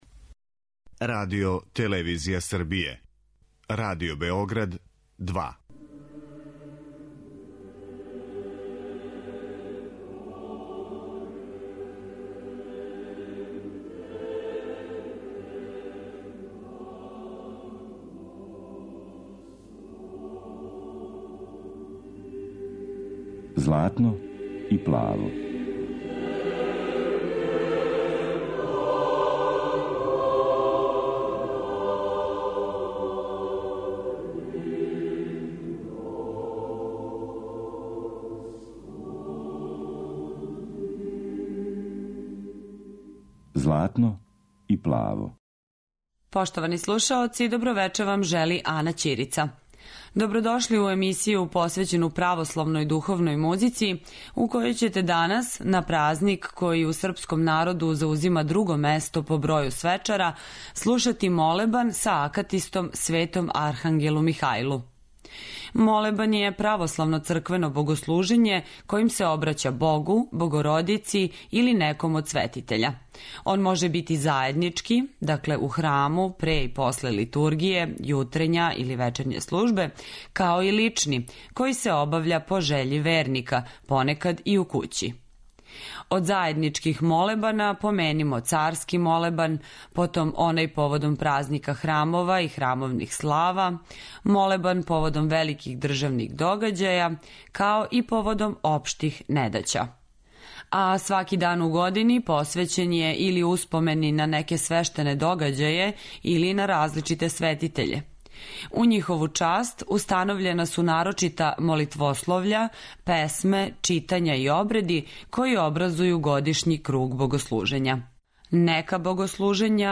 Емисија посвећена православној духовној музици